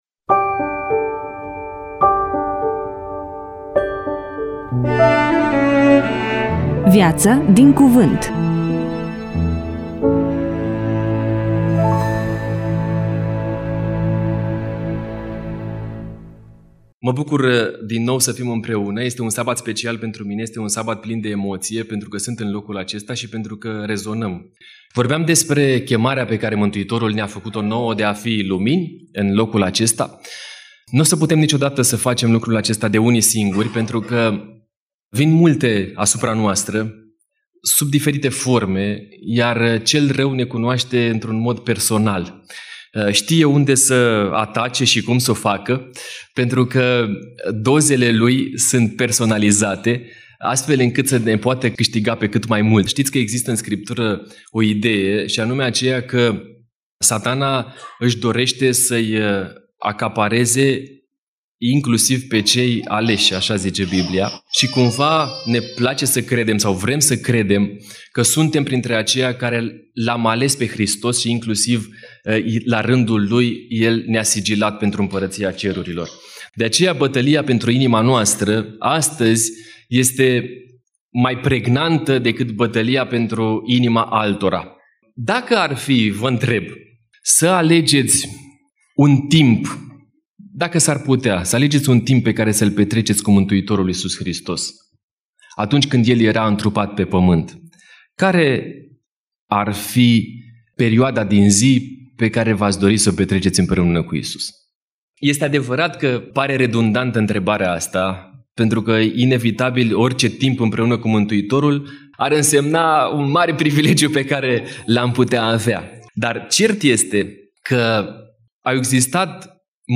EMISIUNEA: Predică DATA INREGISTRARII: 15.11.2025 VIZUALIZARI: 7